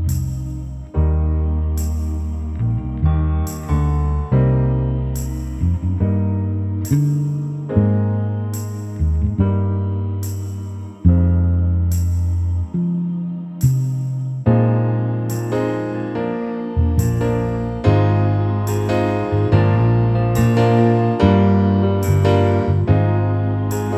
Pop (2010s)